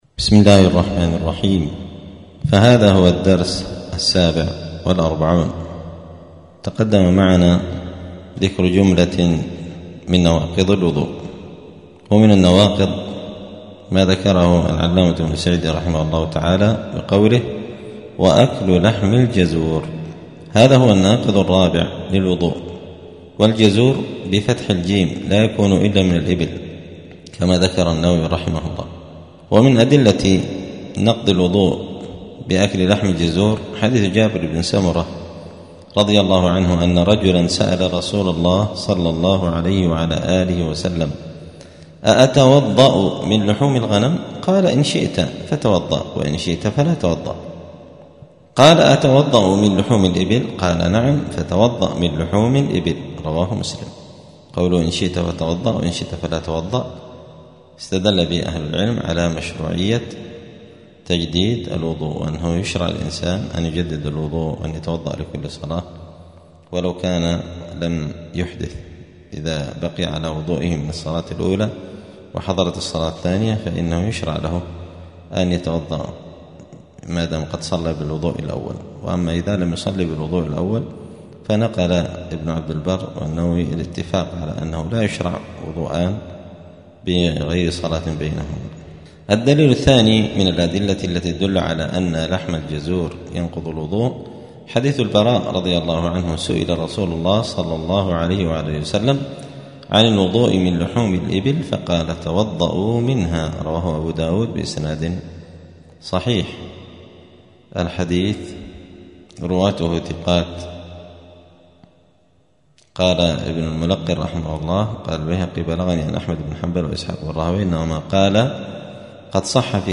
*الدرس السابع والأربعون (47) {كتاب الطهارة باب نواقض الوضوء أكل لحم الجزور}*
دار الحديث السلفية بمسجد الفرقان قشن المهرة اليمن